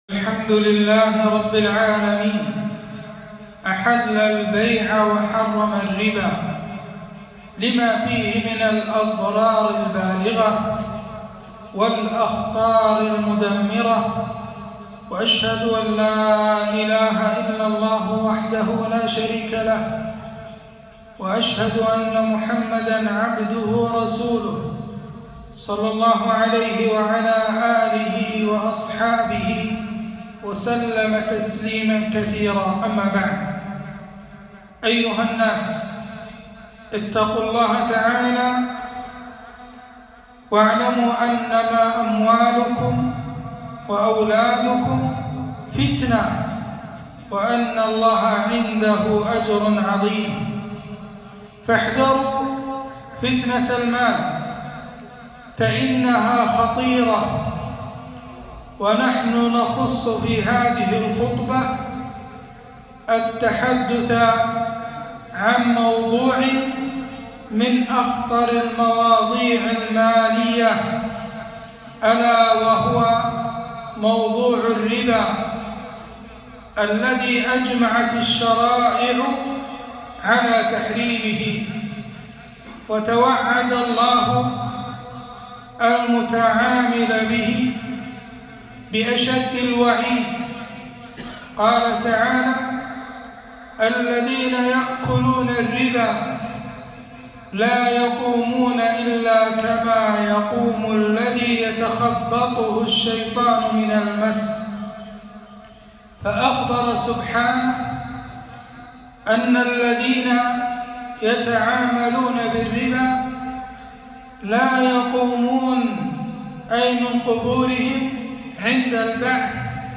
خطبة - وأحل الله البيع وحرم الربا